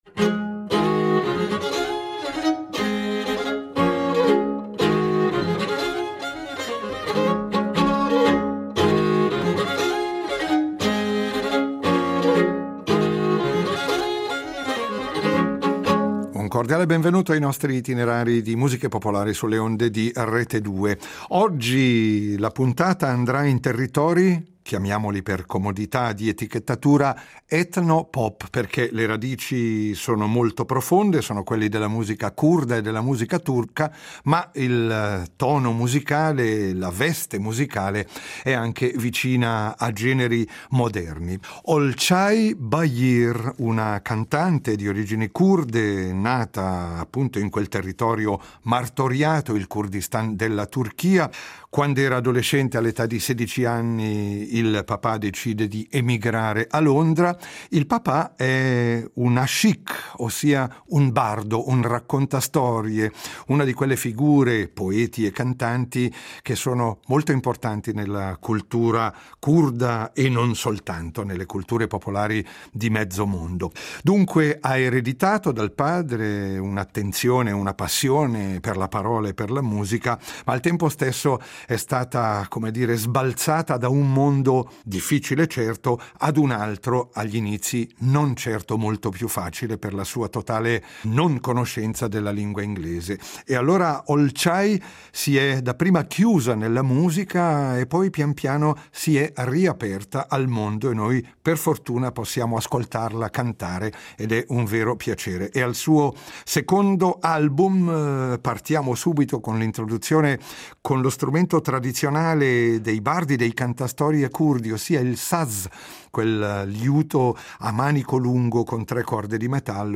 I nostri percorsi folk ci portano questa settimana nell’ambito della moderna musica turca, con un’artista che vive a Londra da tempo e dunque ha forme espressive che rimescolano le sue radici con influssi di vari altri generi.